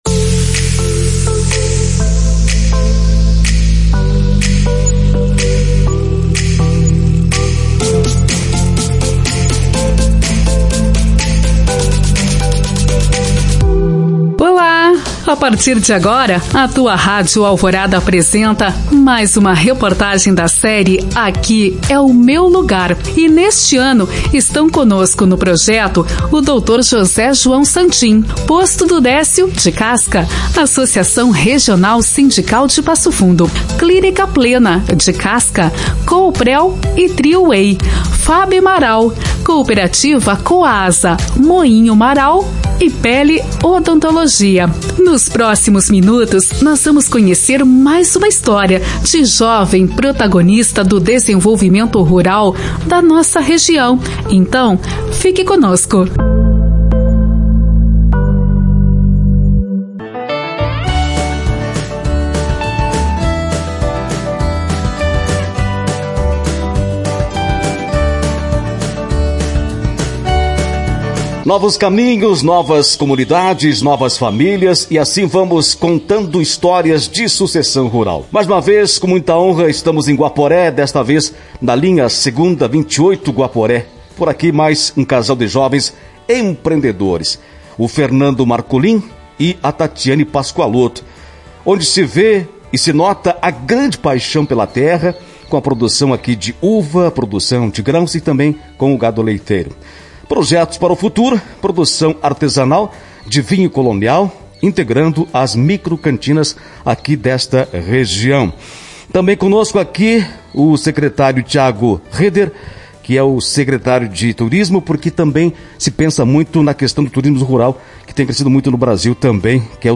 Ouça a reportagem completa clicando no player de áudio e assista o vídeo desse conteúdo em nosso canal do youtube.